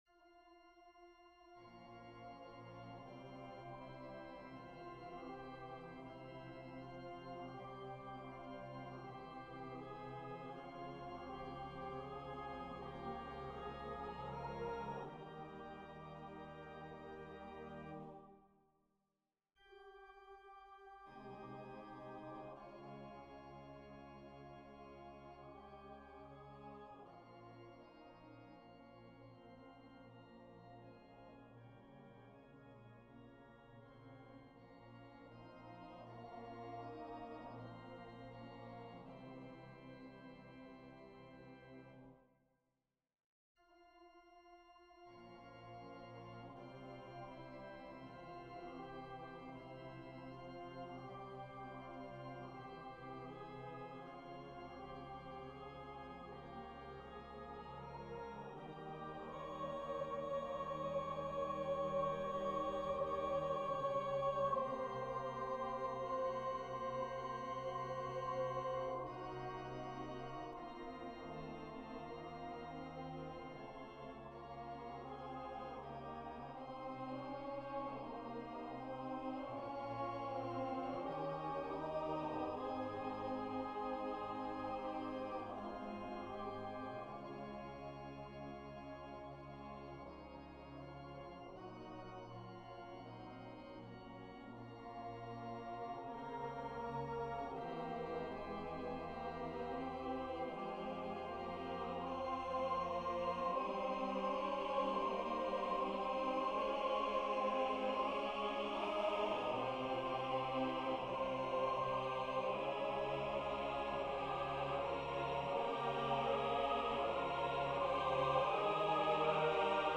choral adaptation
Voicing/Instrumentation: SATB , Organ/Organ Accompaniment